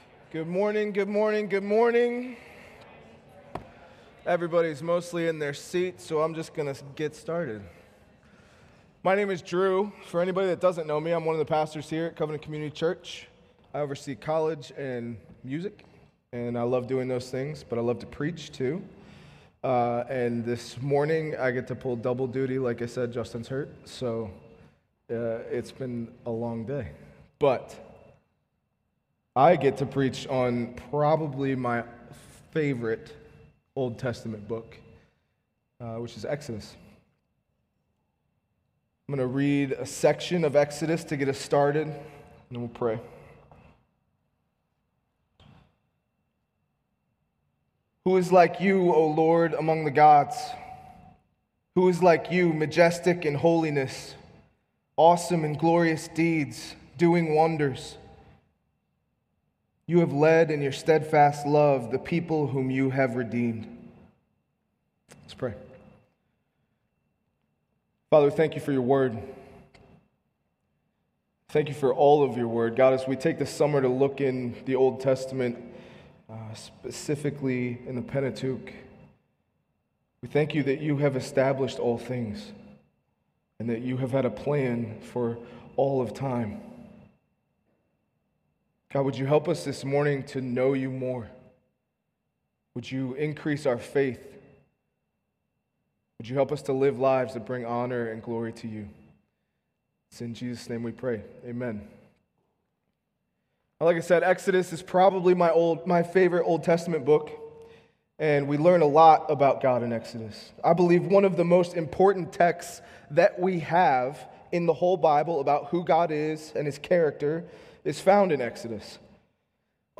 The Pentateuch " "Look in the Book" is an ongoing summer series of sermons focusing on overviews in particular sections of the Bible.